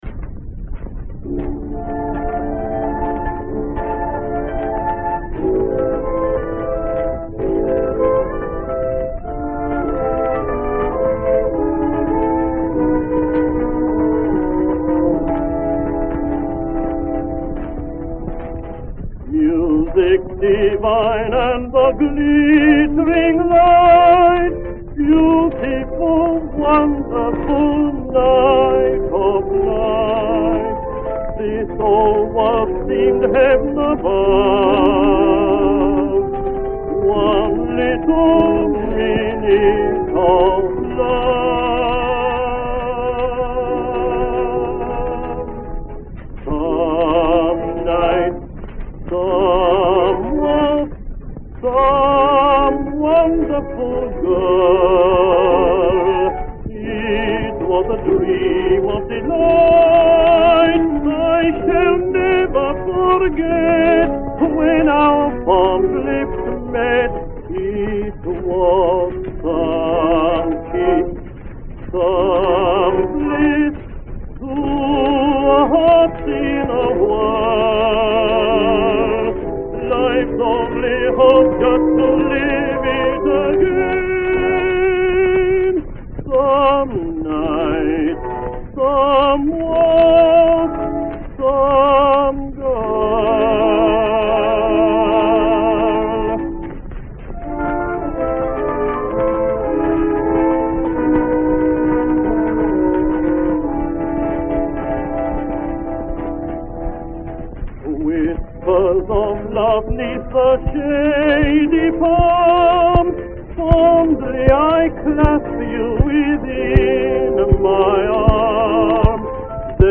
Some Night Waltz
Ernest Pike, as “Herbert Payne” (Zonophone Twin 1628, 1916)